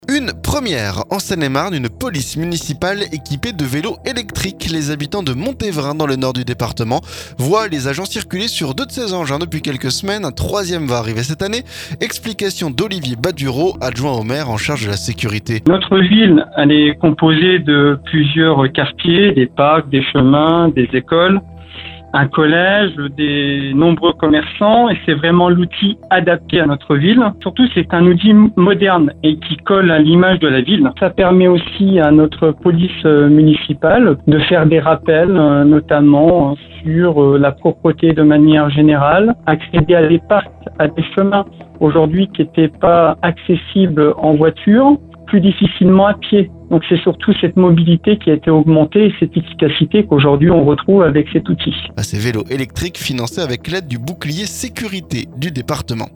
Explications d'Olivier Badureau, adjoint au maire chargé de la sécurité.